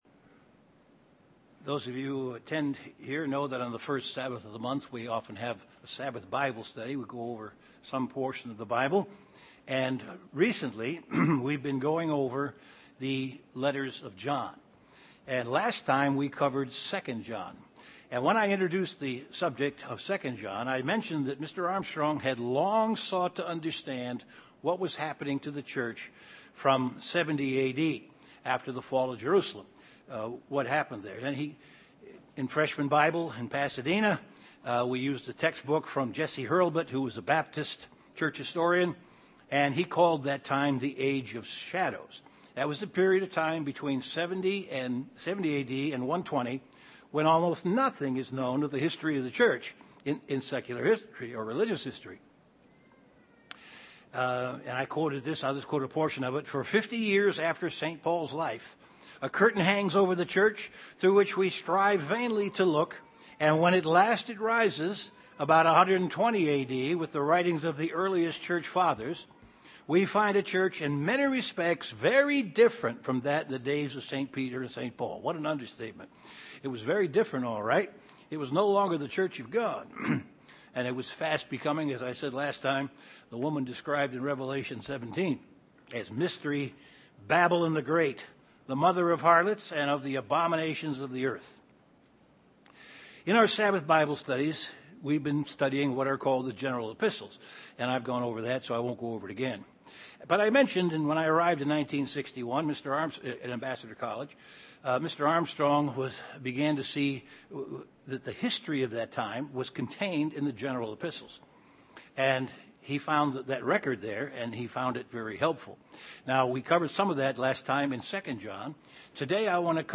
A Bible study and overview of the third letter to John.